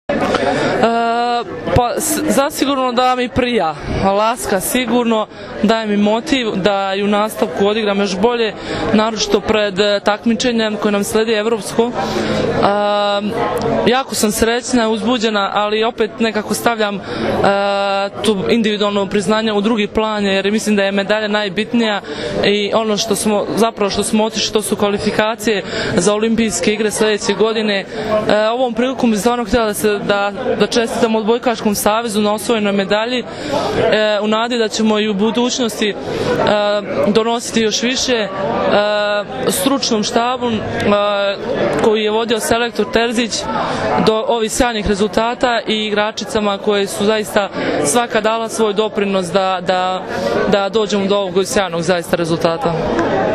IZJAVA BRANKICE MIHAJLOVIĆ